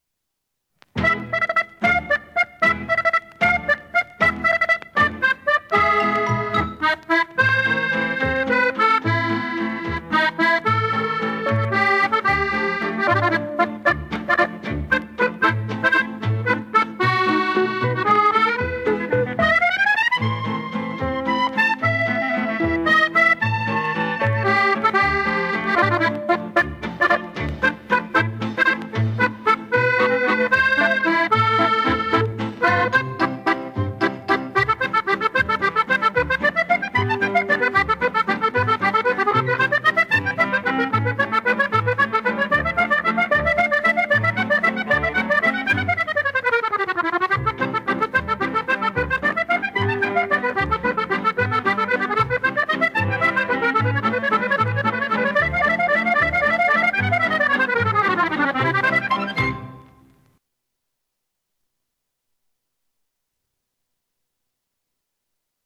princesa-del-acordeon.wav